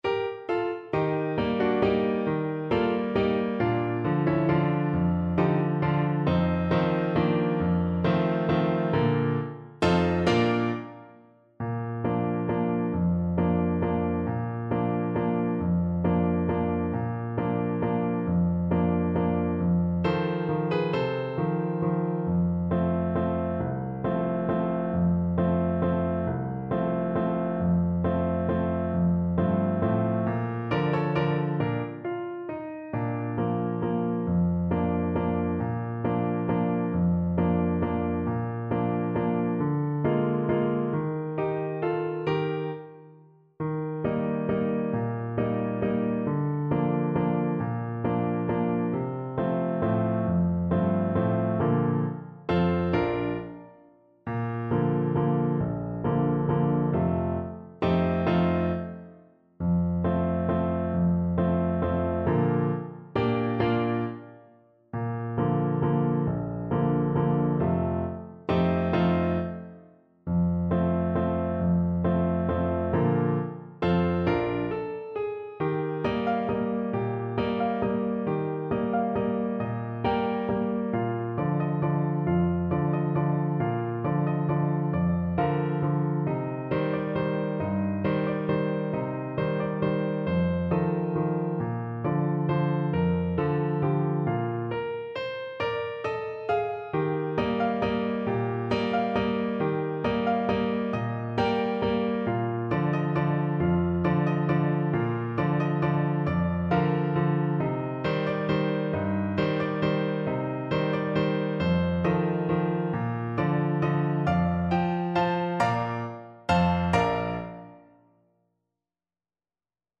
3/4 (View more 3/4 Music)
One in a bar .=c.60